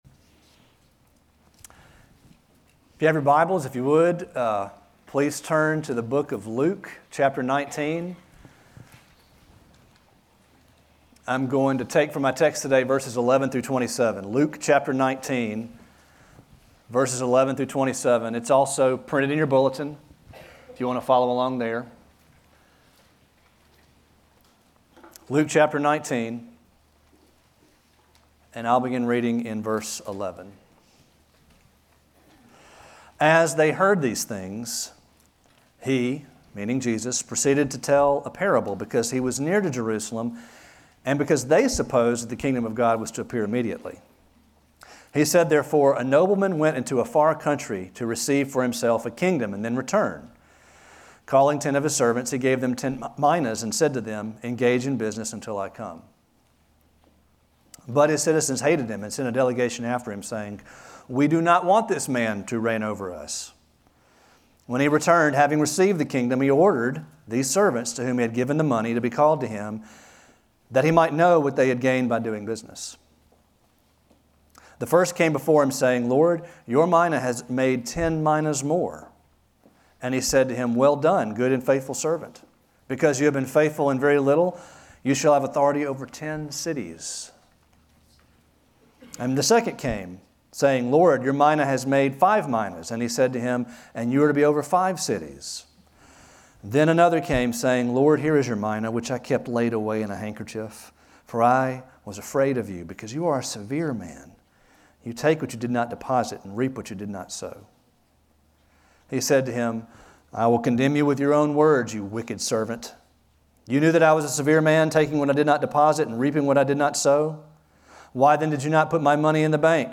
Sermons | Grace Bible Church of Oxford